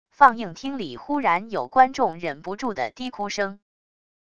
放映厅里忽然有观众忍不住的低哭声wav音频